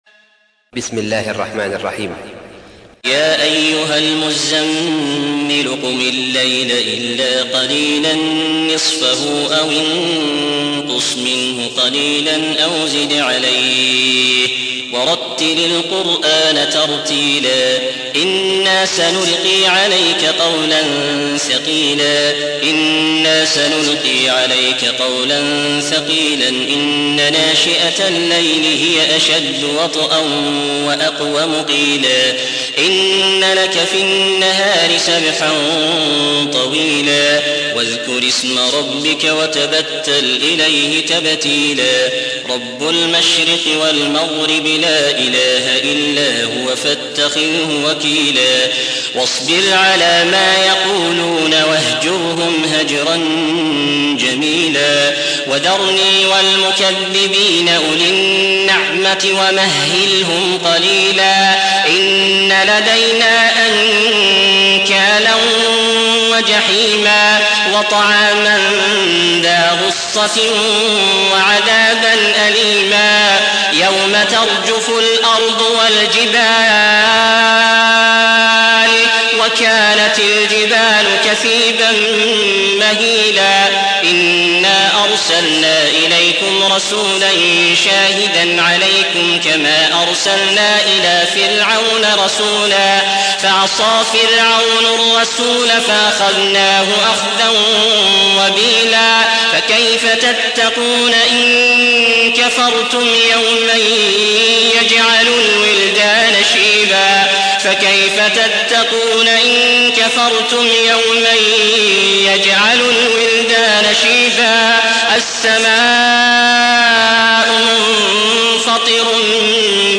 تحميل : 73. سورة المزمل / القارئ عبد العزيز الأحمد / القرآن الكريم / موقع يا حسين